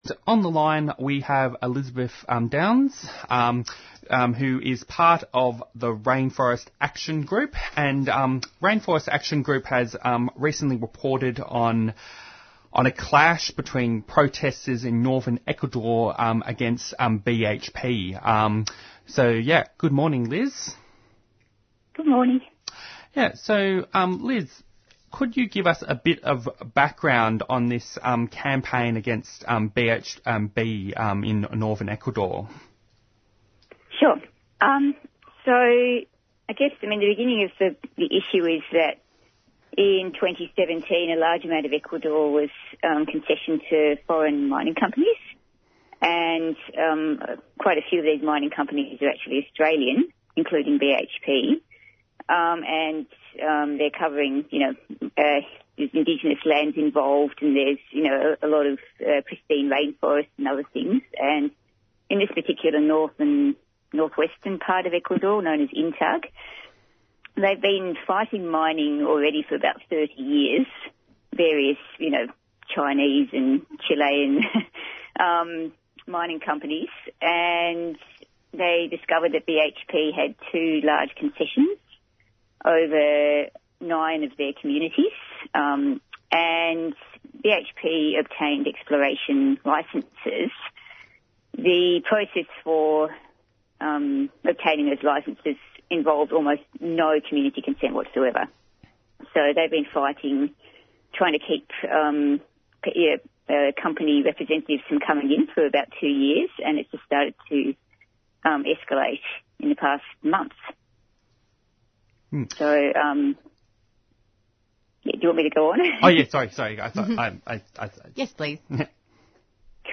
Download and listen to the interview on 3CR's Green Left Radio here.